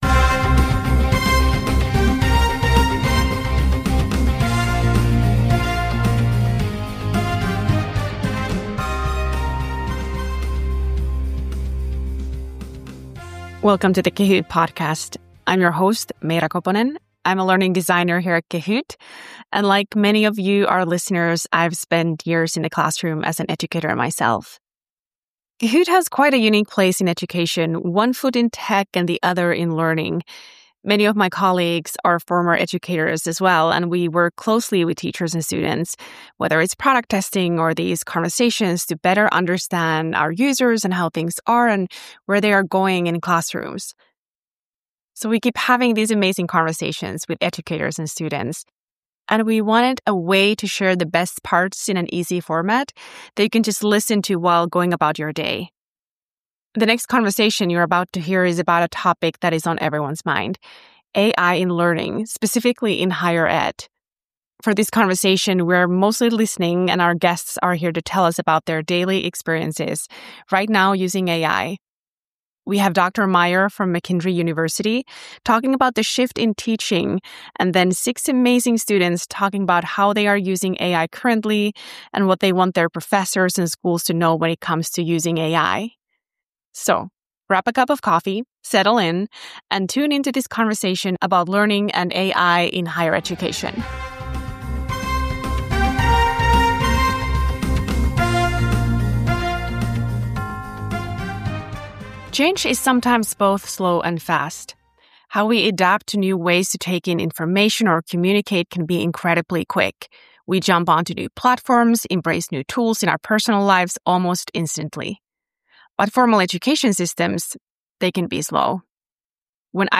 Conversamos com professores e estudantes para ouvir suas opiniões e entender como eles estão incorporando a IA em sua rotina acadêmica.